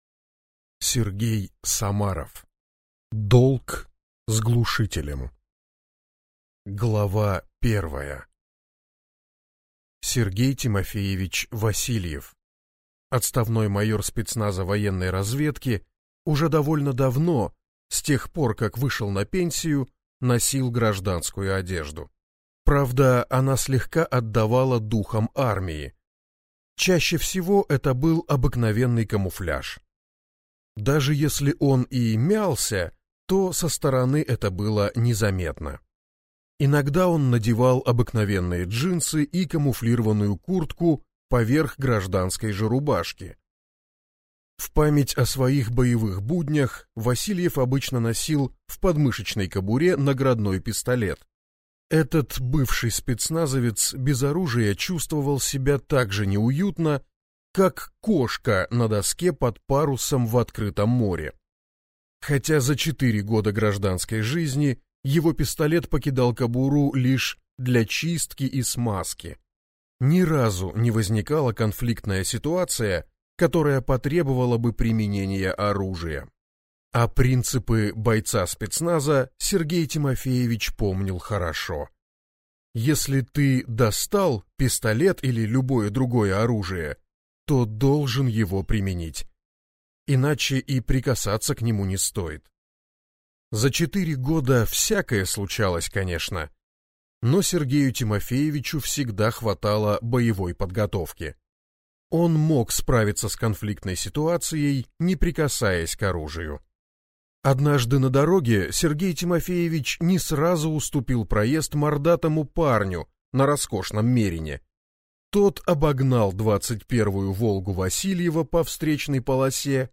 Аудиокнига Долг с глушителем | Библиотека аудиокниг